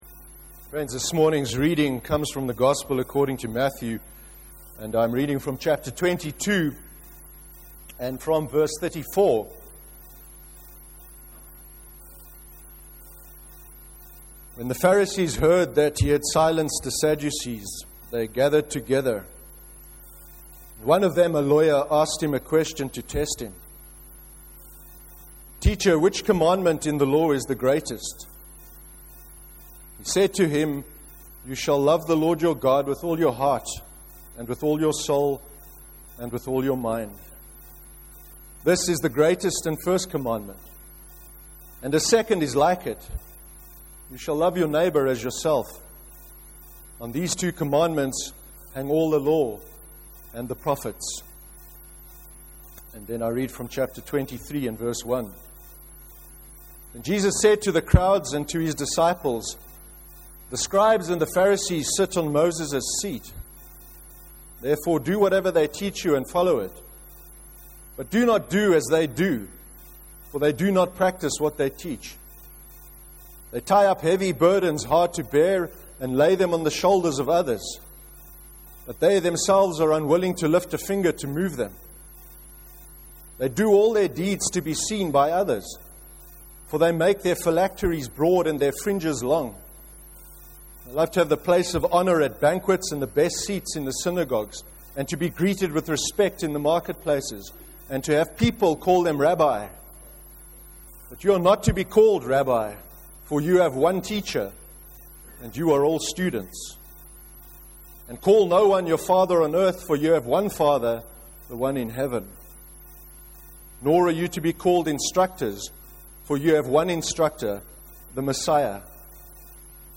30/06/13 sermon – A summary of Jesus’ message in the Sermon on the Mount (Matthew 22:34-40 and Matthew 23:1-23).